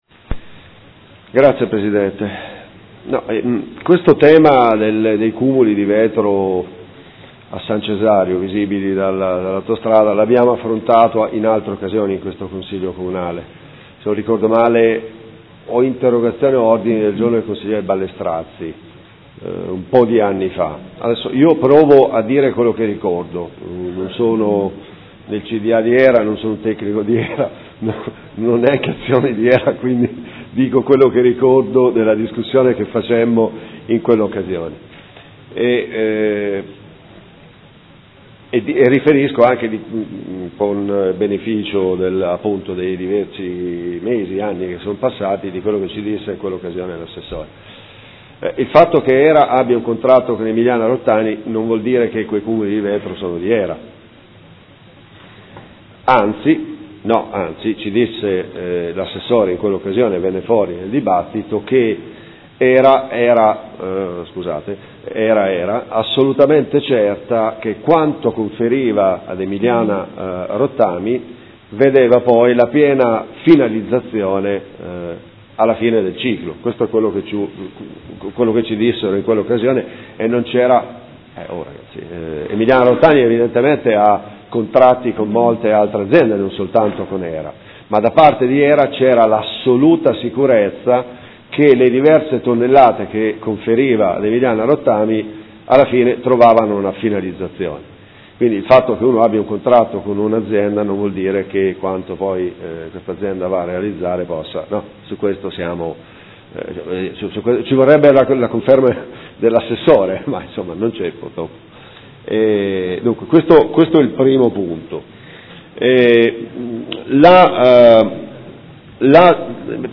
Seduta del 17/12/2015 Ordine del Giorno presentato dal Gruppo Consiliare Movimento 5 Stelle avente per oggetto: Hera ed Emiliana Rottami